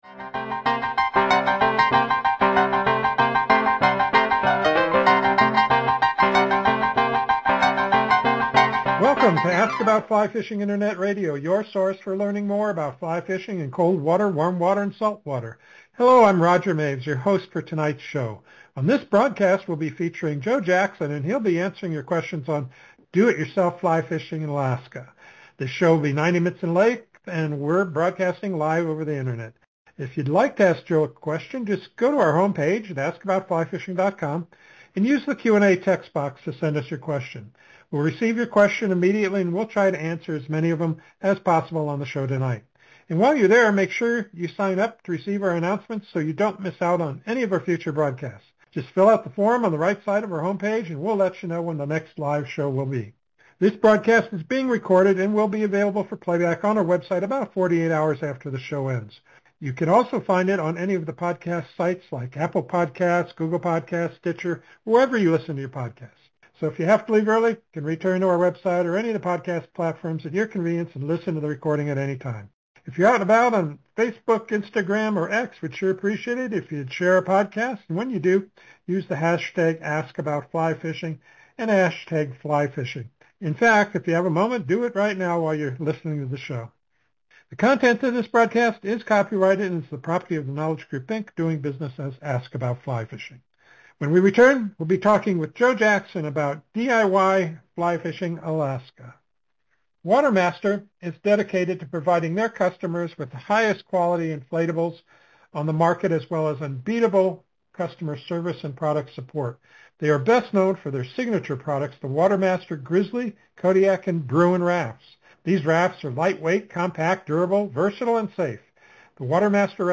Just a few of the questions asked and answered during the interview: